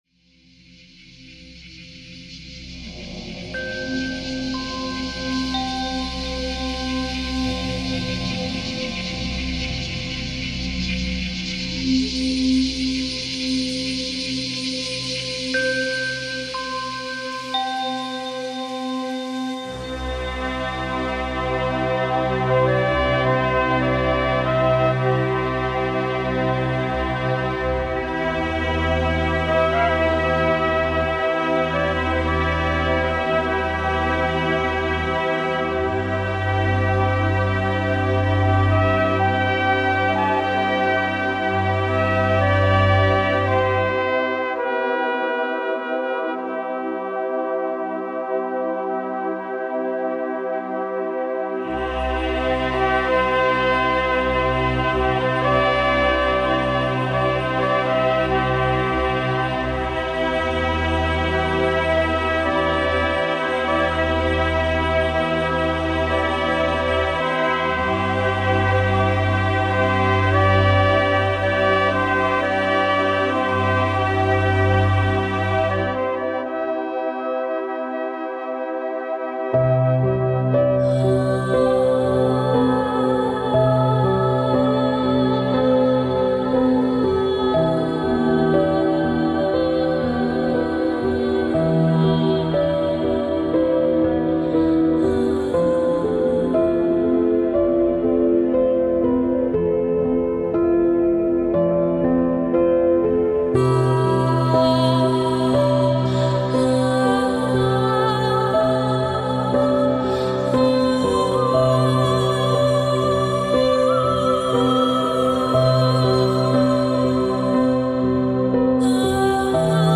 Wellness- und Entspannungsmusik